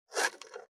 471,咀嚼音,ナイフ,調理音,まな板の上,料理,
効果音厨房/台所/レストラン/kitchen食器食材
効果音